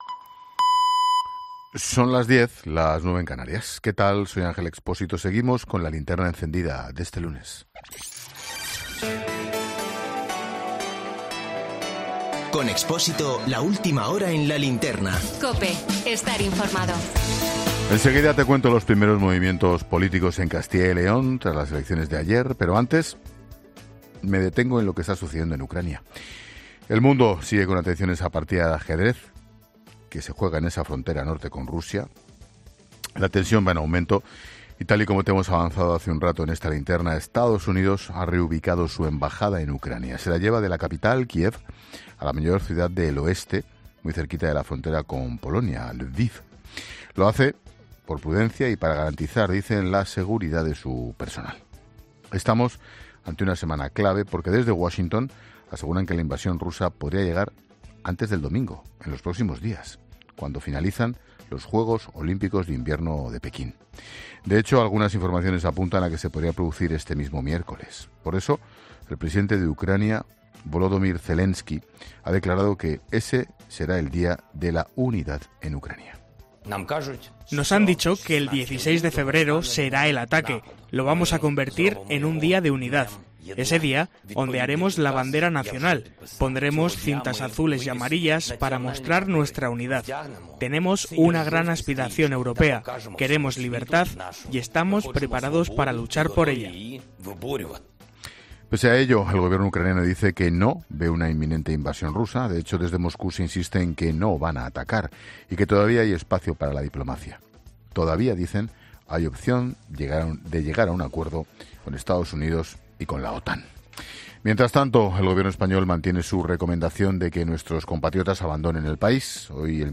AUDIO: Monólogo de Expósito.